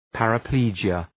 Shkrimi fonetik{,pærə’pli:dʒıə}